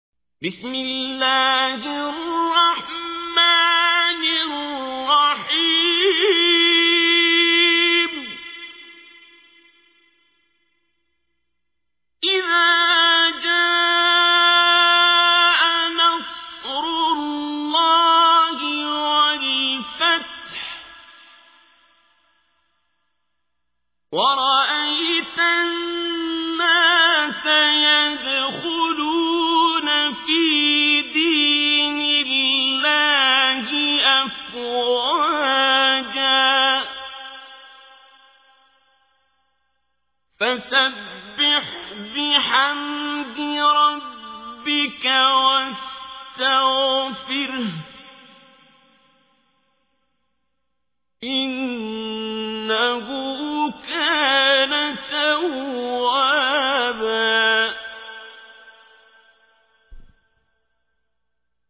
تحميل : 110. سورة النصر / القارئ عبد الباسط عبد الصمد / القرآن الكريم / موقع يا حسين